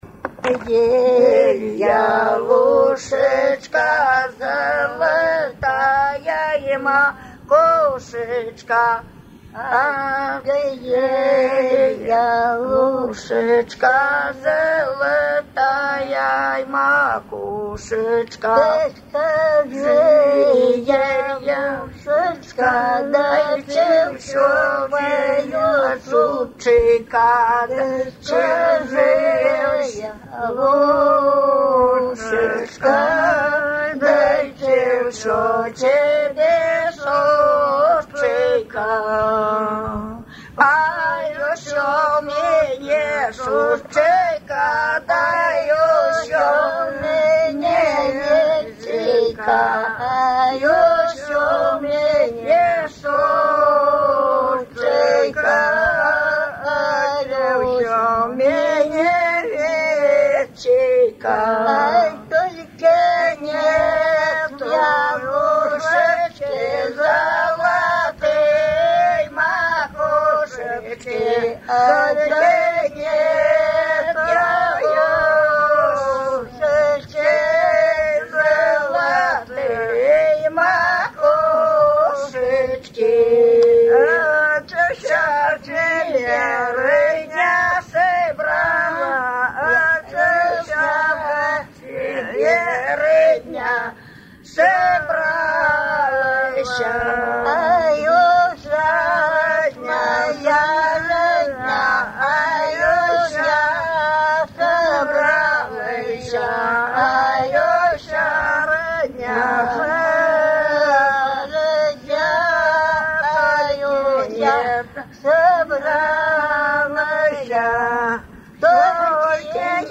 Свадебные обрядовые песни в традиции верховья Ловати
«Ах, ты ель–ялушечка» Песня невесте сироте исп.